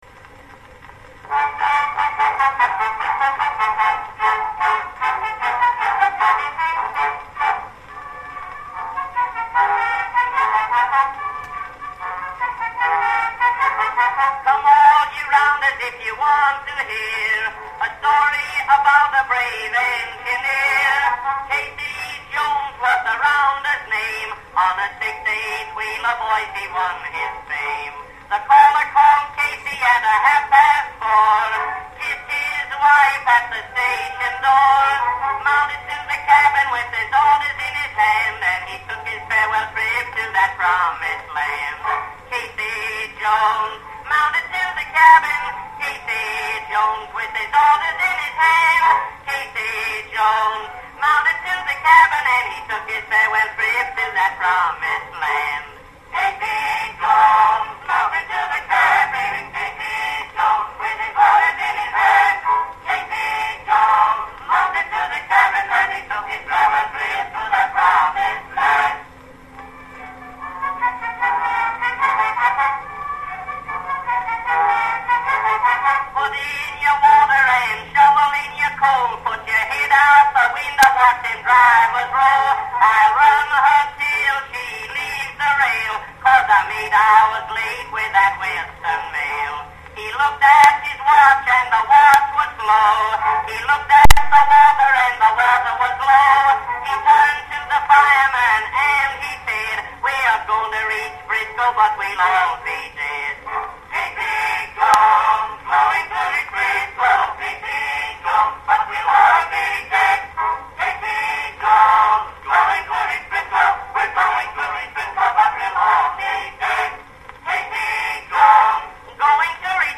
Cyberbee's Edison Cylinder Recordings
Listen to Casey Jones recorded by Billy Murray & Chorus 1912 on a 4M Blue Amberol